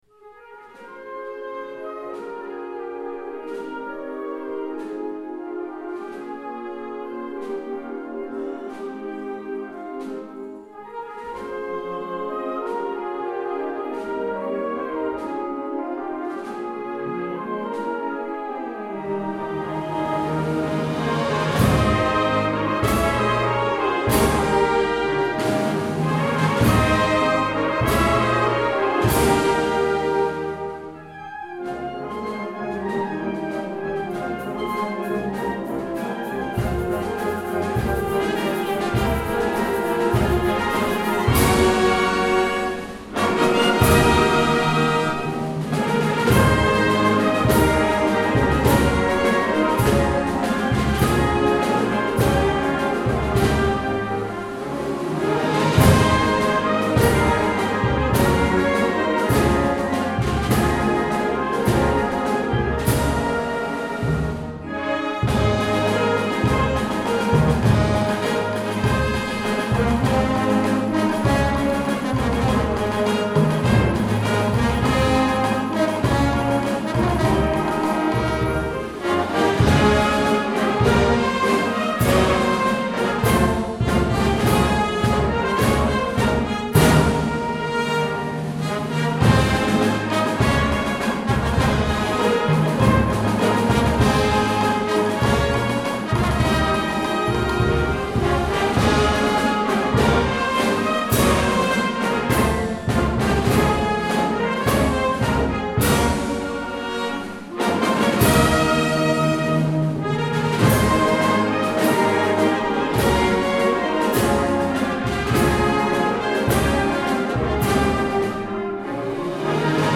Concert de Setmana Santa - Auditori de Porreres.